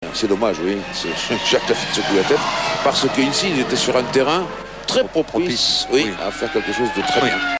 Grand Prix d'ALLEMAGNE 2004
sur TF1
Jacques sifflote sur l'hymne Allemand...
...et chantonne sur l'hymne Italien !